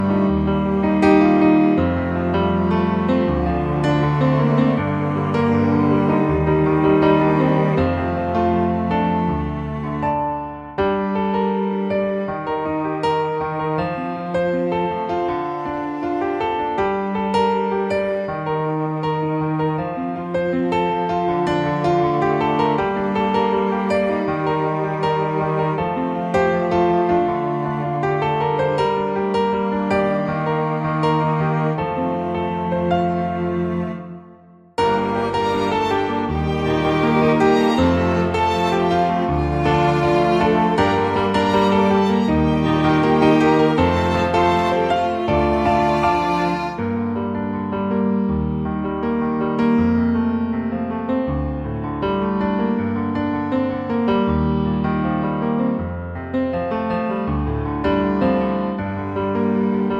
Original Key for Duet